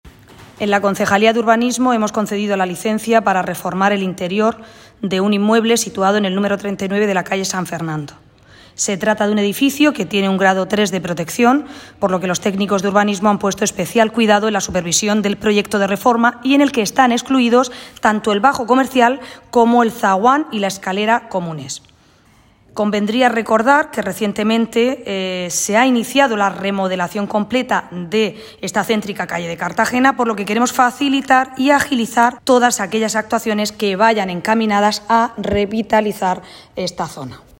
Enlace a Declaraciones de Ana Belén Castejón